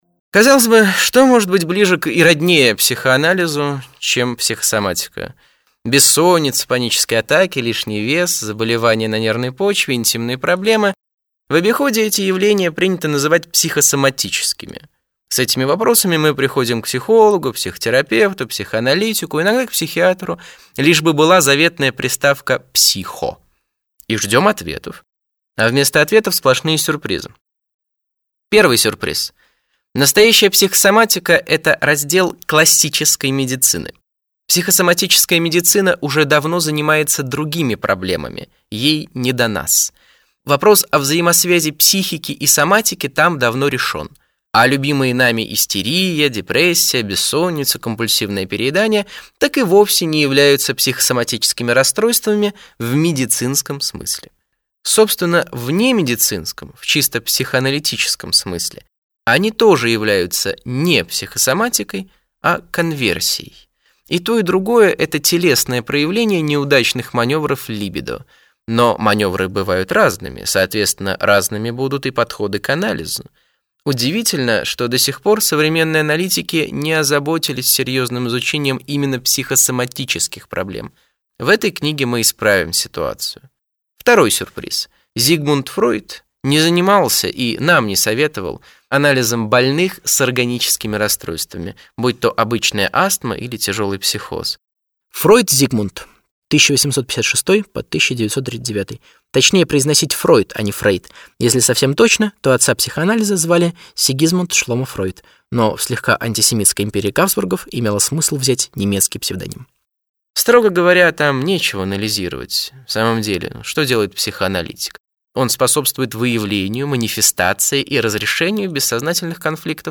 Аудиокнига Вся фигня – от мозга?!